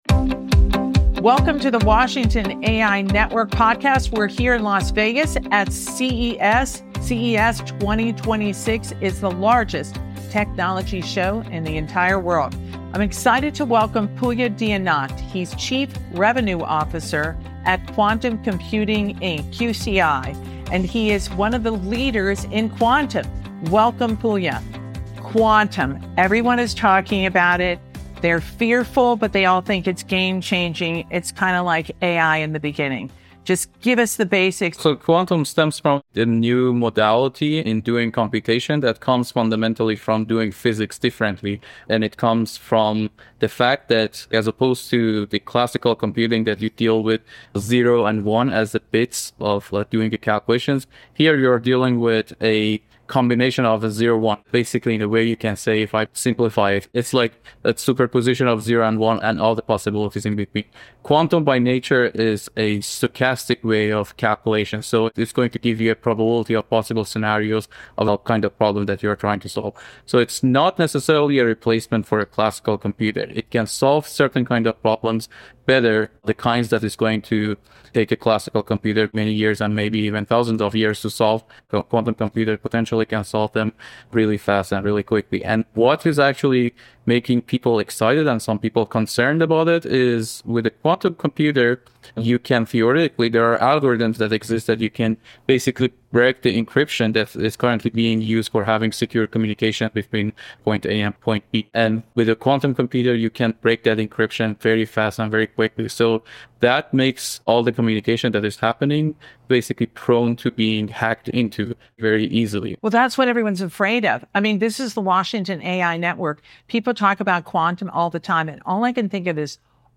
Recorded at CES 2026, this special episode of the Washington AI Network Podcast examines how quantum computing and AI tools are moving from theory into real-world use.